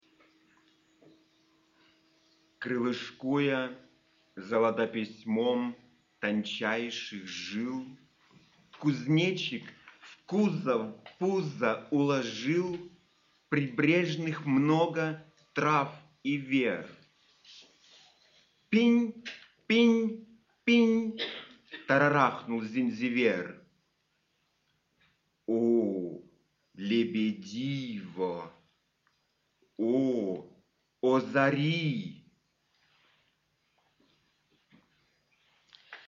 Mittschnitt aus der Veranstaltung „Segel der Zeit“ anlässlich des hundertsten Geburtstags von Welimir Chlebnikov im Lindenau Museum Altenburg 1985.